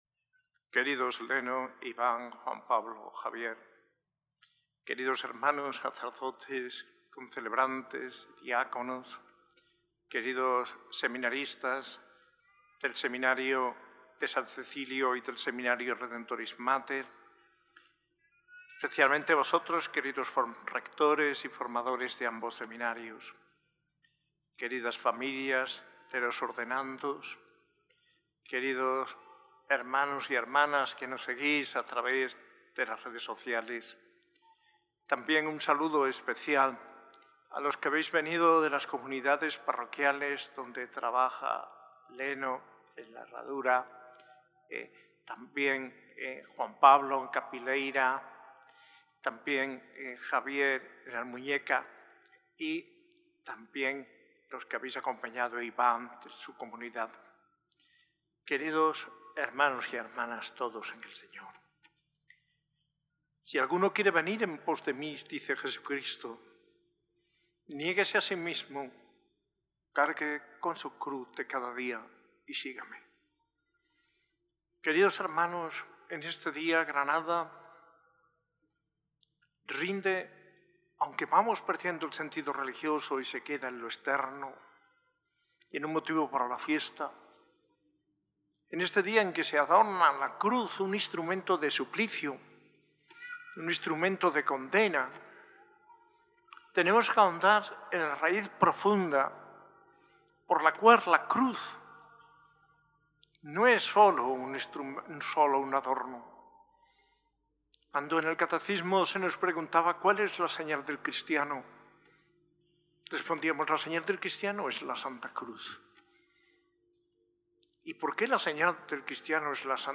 Homilía del arzobispo de Granada, Mons. José María Gil Tamayo, en las ordenaciones diaconales del 3 de mayo de 2025, en la S.A.I Catedral.